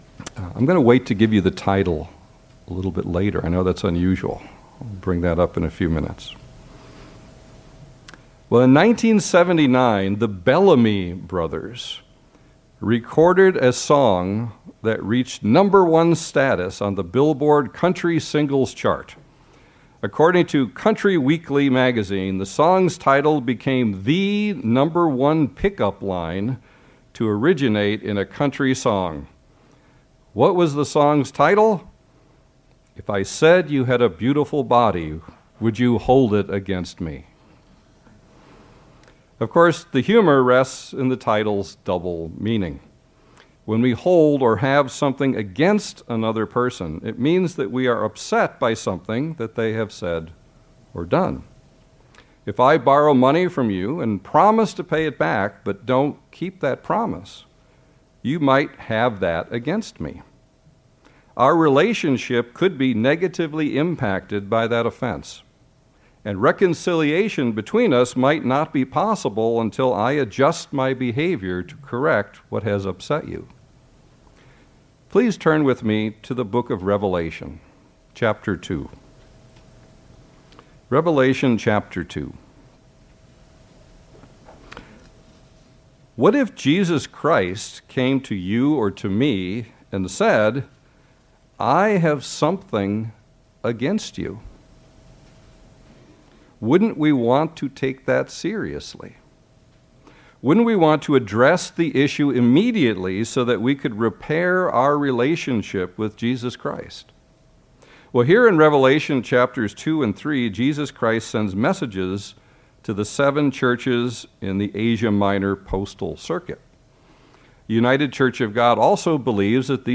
How can we actually go about overcoming Satan and the influences of his world? The answer is found in Revelation 12:11, as this sermon explains.